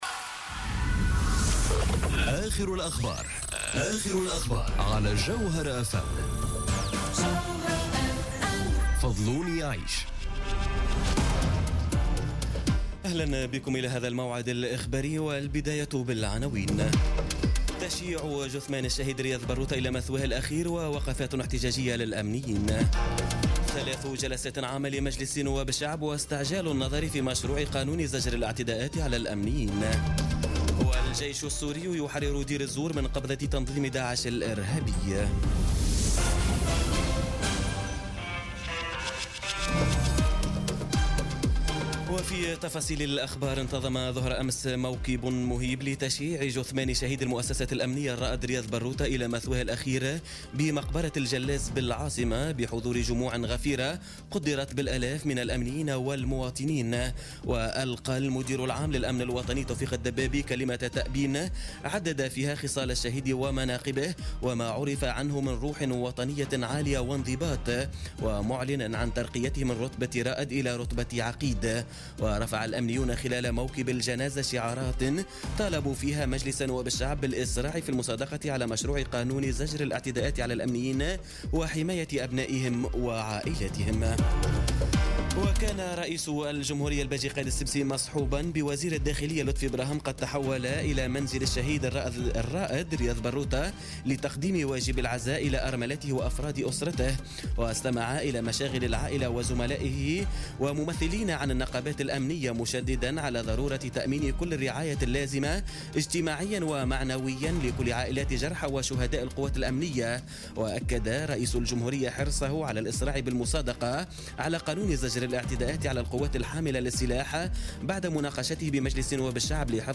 نشرة أخبار منتصف الليل ليوم السبت 4 نوفمبر 2017